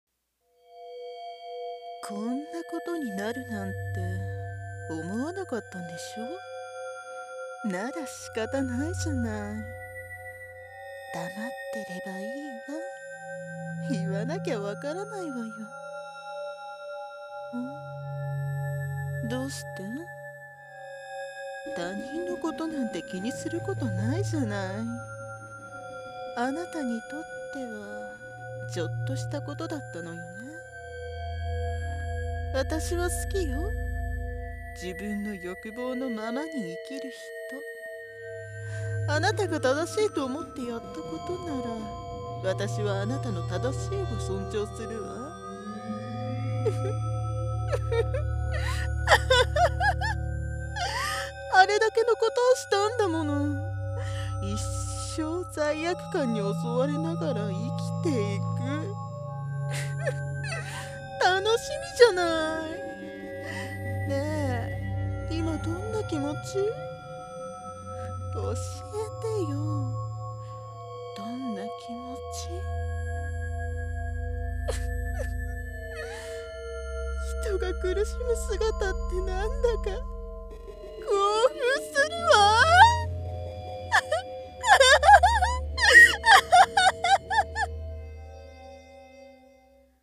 【声劇】どんな気持ち？